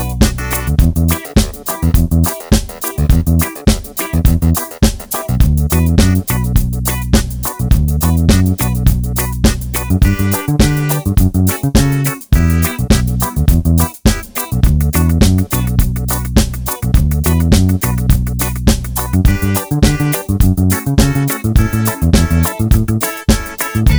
no lead guitar no Backing Vocals Reggae 3:55 Buy £1.50